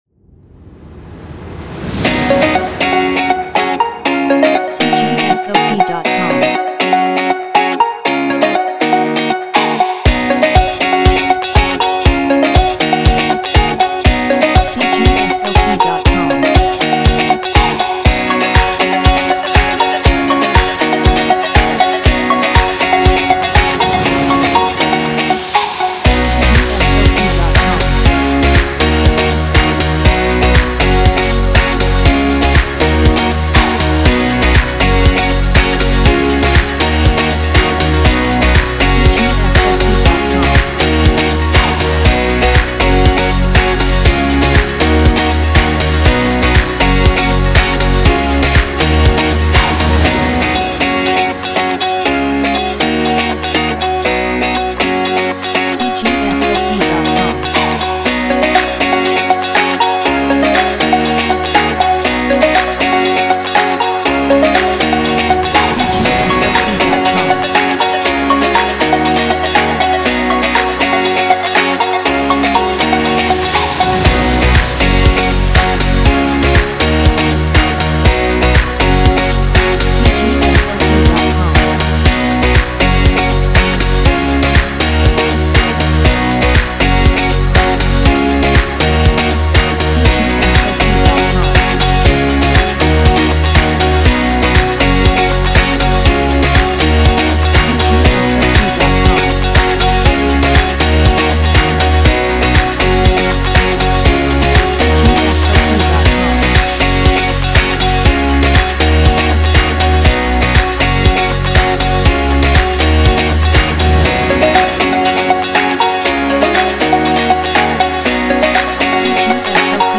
16-Bit Stereo
克里思:122 BPM
人声:没有人声